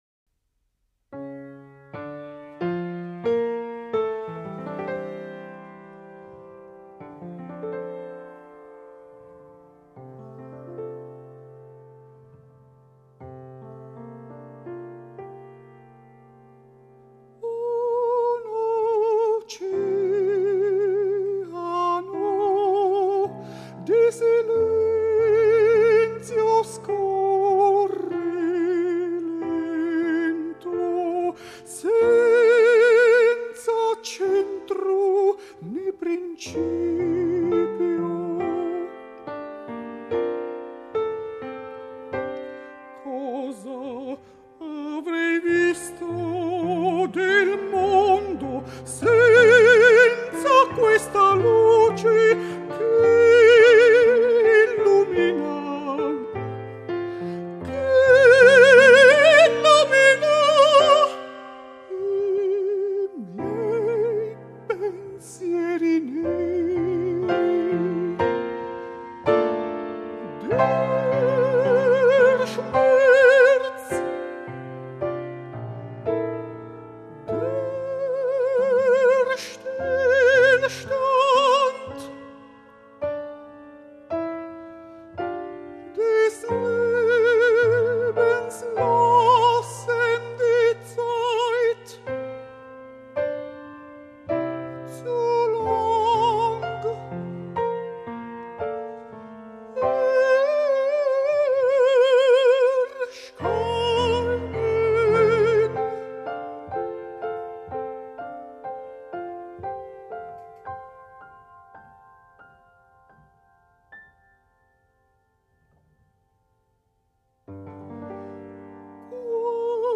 Dieci lieder per dieci canzoni
Sopranista
pianista